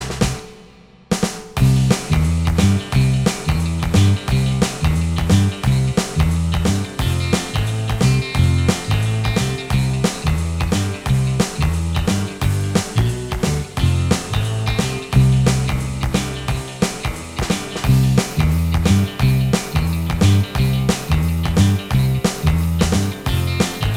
Minus Solo Guitar Pop (1960s) 2:16 Buy £1.50